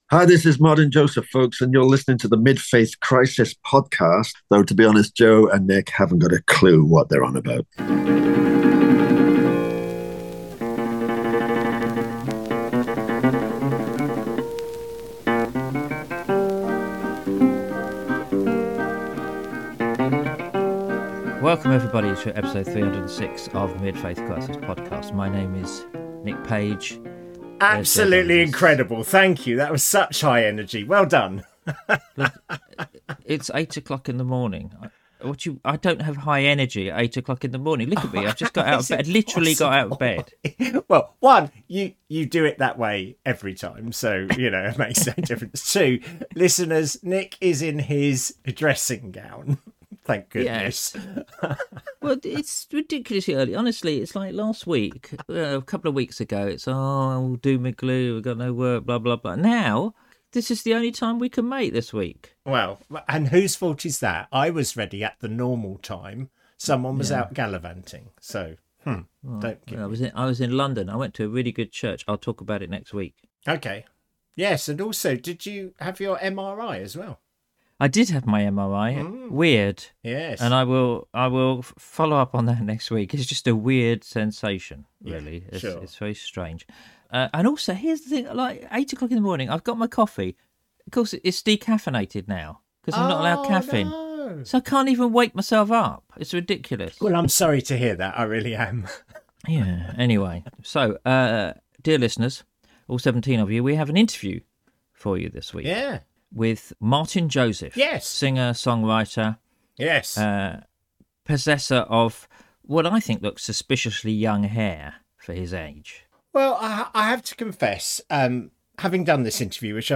Episode 306: An interview with Martyn Joseph
We have an interview with singer-songwriter Martyn Joseph. He talks about his career, the craft of song-writing, his campaigning worked the importance of just turning up. And we end, of course, with a song.